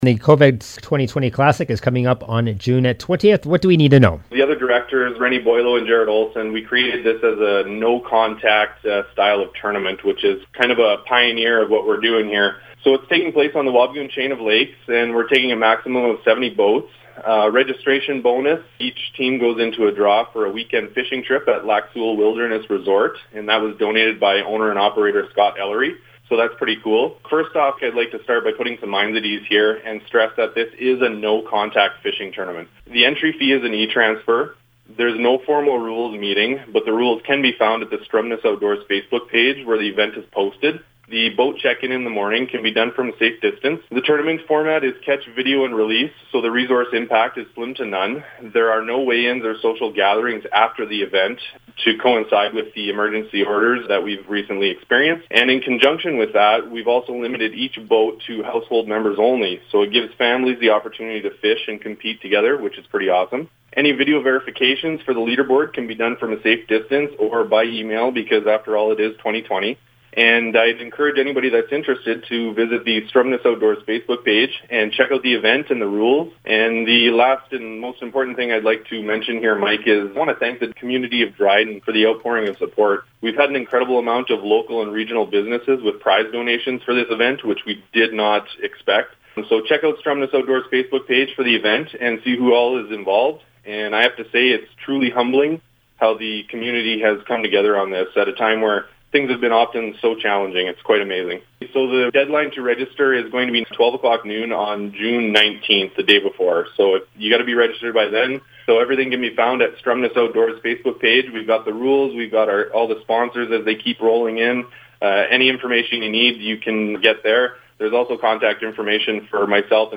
was a guest on the CKDR Morning Show Friday